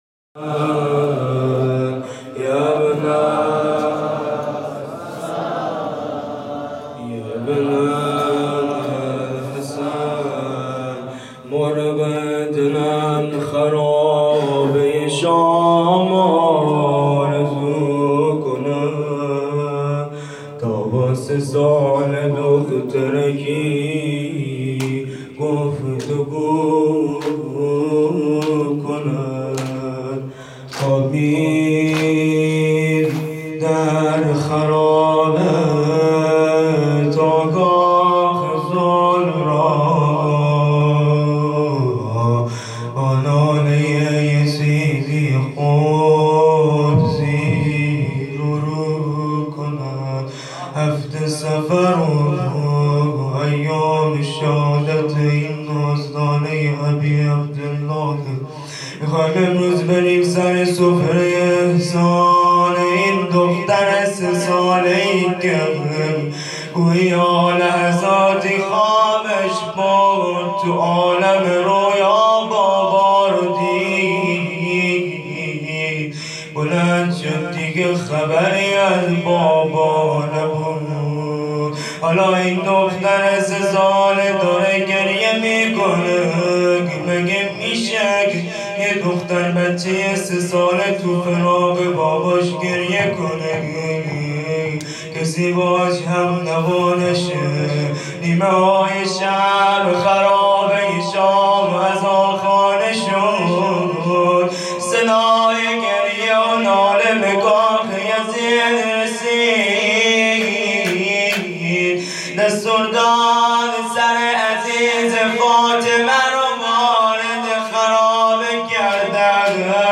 خیمه گاه - کمیت 135 - روضه حضرت رقیه
روز شهادت بی بی رقیه هیات قاسم ابن الحسن